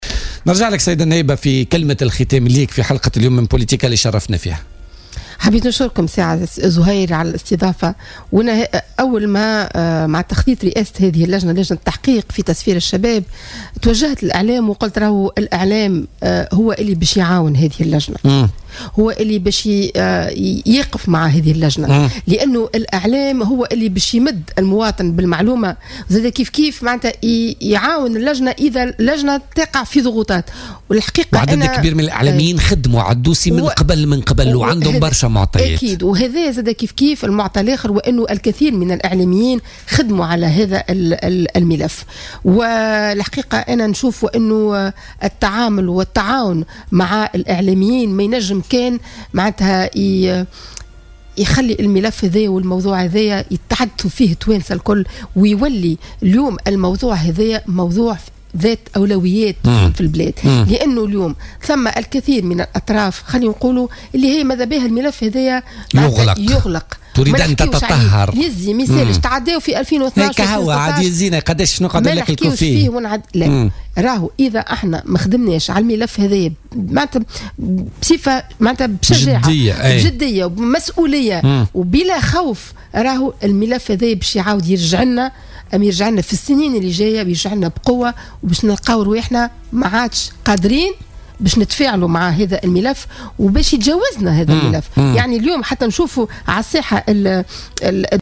وقالت ضيفة بوليتيكا، التي انضمت مجددا إلى هذه اللجنة بعد التحاقها بحركة مشروع تونس، إن التحقيقات كشفت عن هذه العلاقة وهي نقطة من النقاط، التي تكشفها لأول مرة، بحسب تعبيرها، مشيرة إلى وجود ضغوطات ومحاولات لغلق ملف التسفير إلى سوريا.